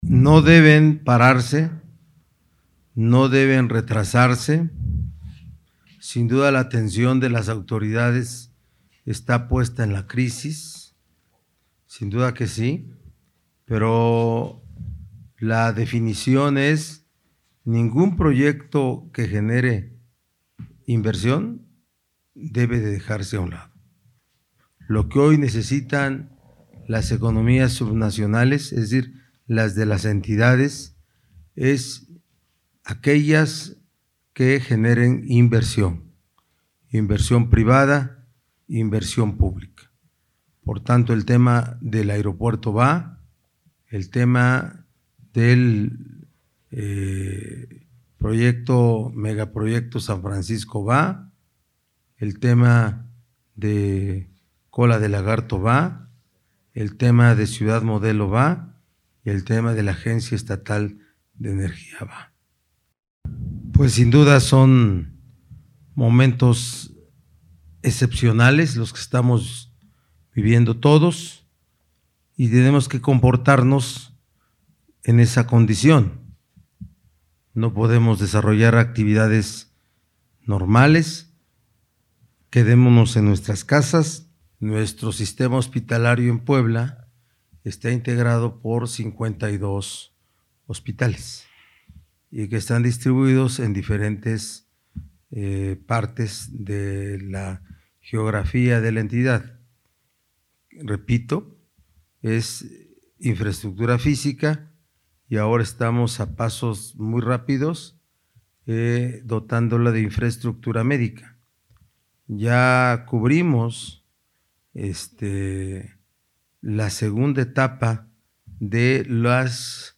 Al presidir una rueda de prensa en Casa Aguayo, el titular del Ejecutivo advirtió que los líderes criminales que aún no han sido detenidos, están claramente identificados, por lo que serán encontrados, aprehendidos y procesados.
En esta conferencia de prensa, en la que estuvo acompañado por el secretario de Gobernación, David Méndez Márquez, subrayó que Raciel López está dinamizando el sistema policial en todo el estado.